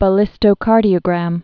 (bə-lĭstō-kärdē-ə-grăf)